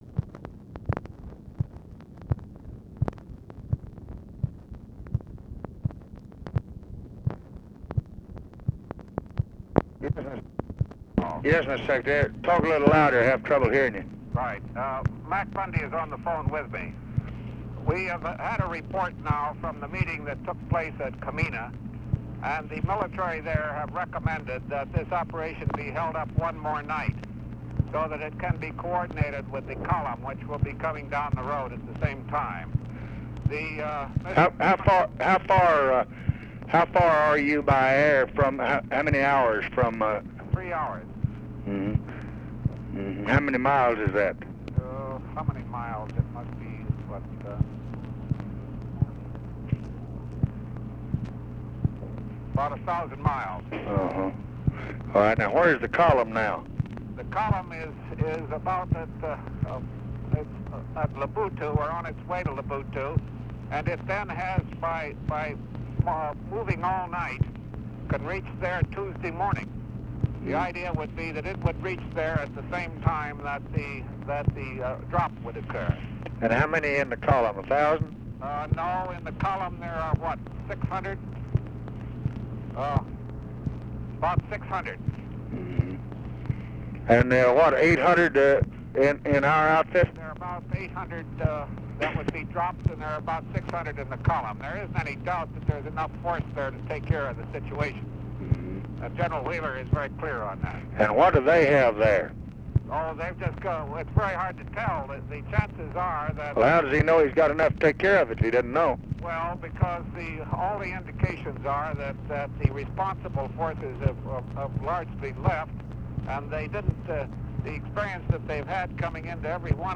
Conversation with GEORGE BALL and MCGEORGE BUNDY, November 22, 1964
Secret White House Tapes